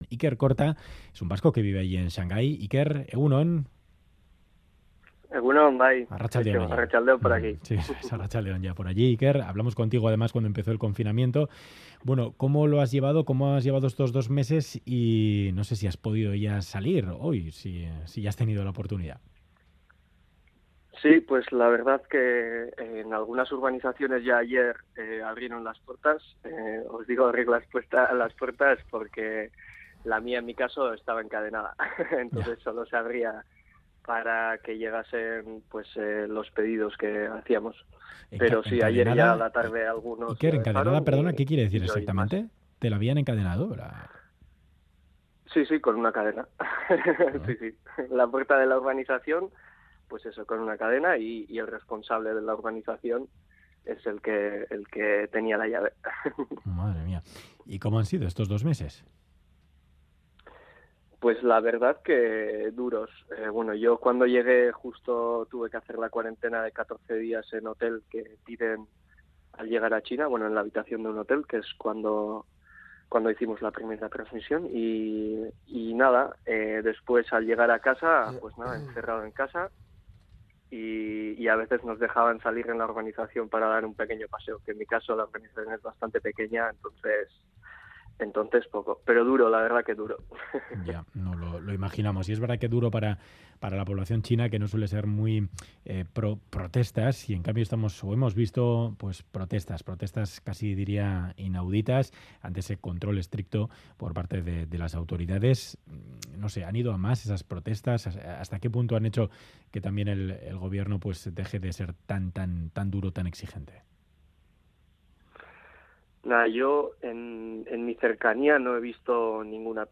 Radio Euskadi ENTREVISTA